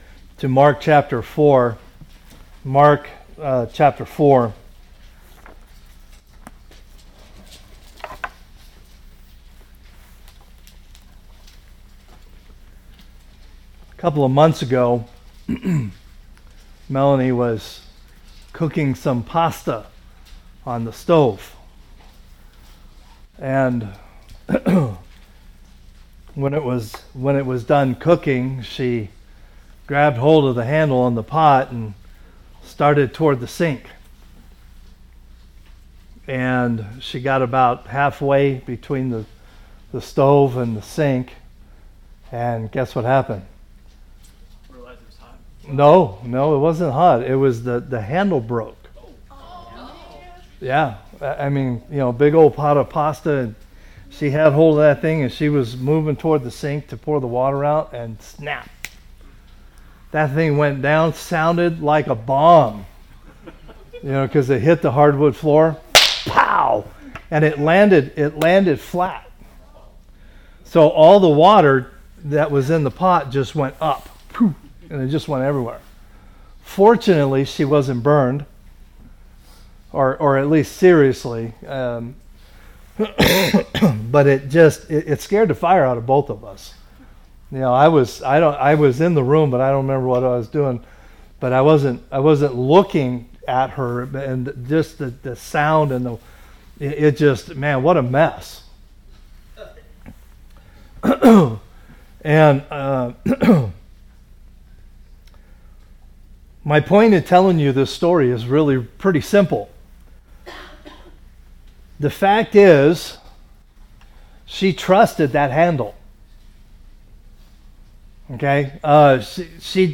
A message from the series "General Series."